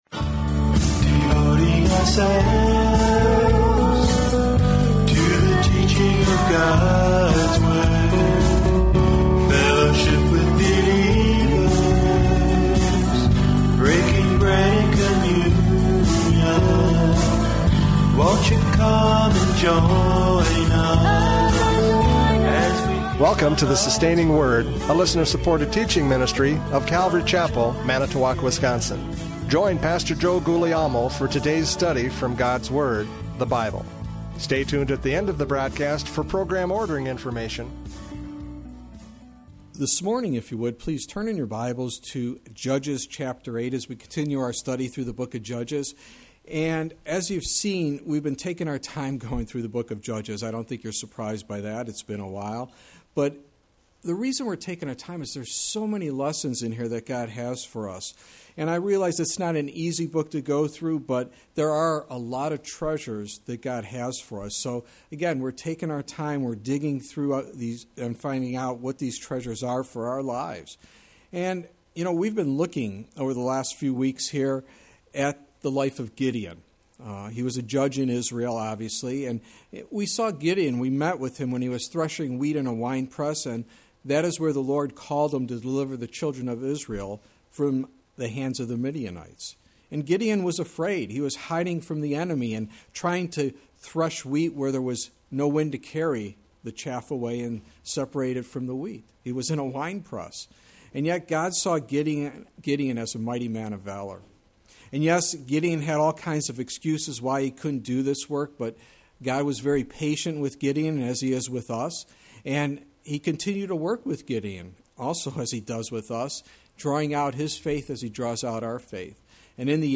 Judges 8:22-35 Service Type: Radio Programs « Judges 8:4-21 Perusing the Enemy!